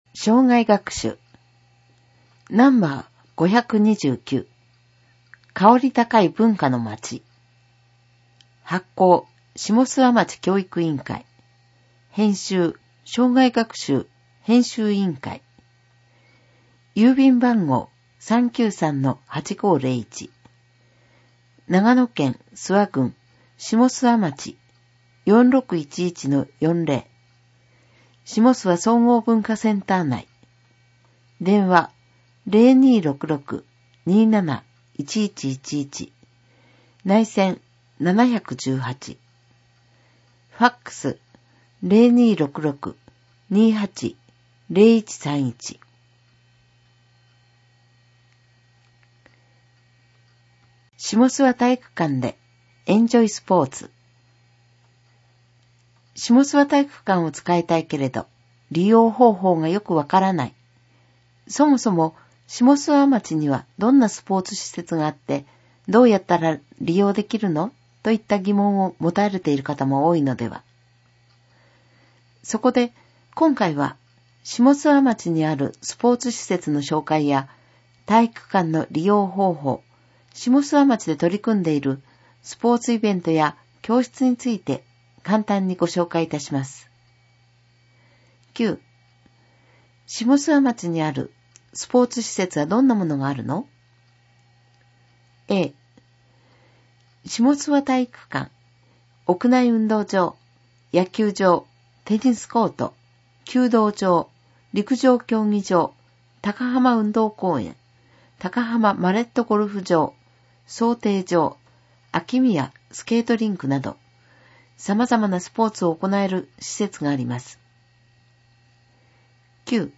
ダウンロード （音読版）クローズアップしもすわ2018年6月号 [ mp3 type：23MB ] （音読版）生涯学習５２９号 [ mp3 type：6MB ] 添付資料を見るためにはビューワソフトが必要な場合があります。